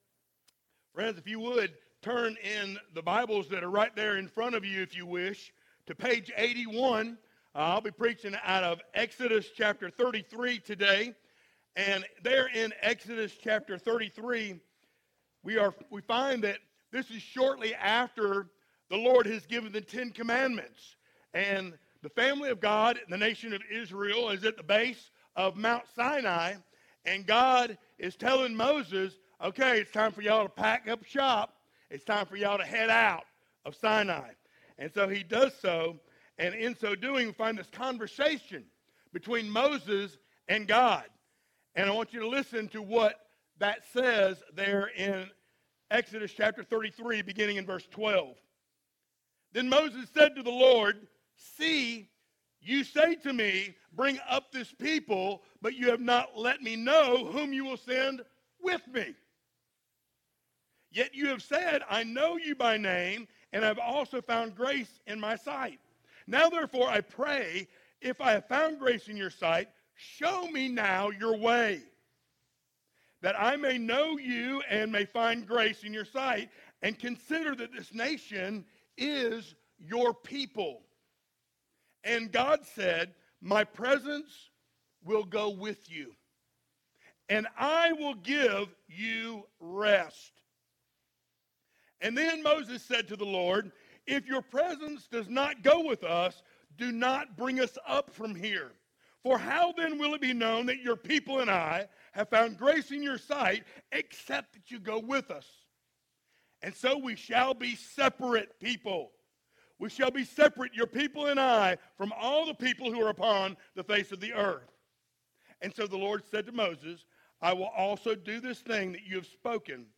Series: sermons